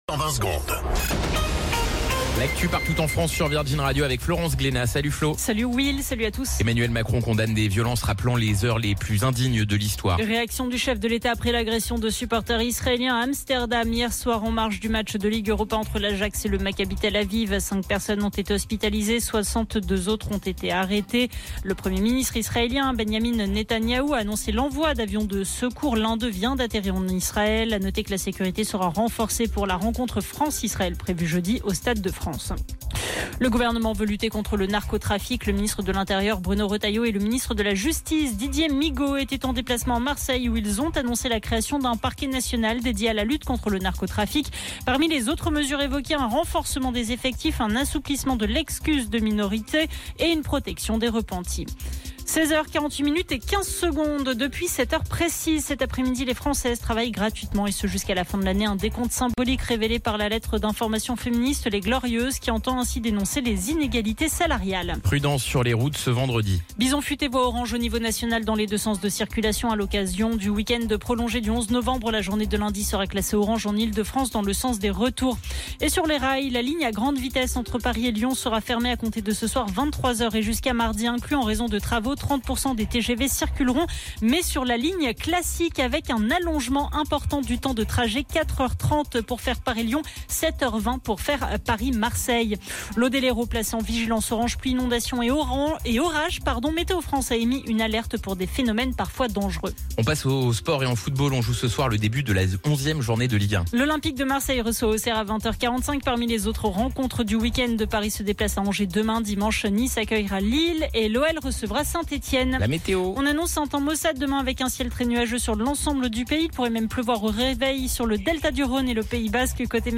Flash Info National 08 Novembre 2024 Du 08/11/2024 à 17h10 .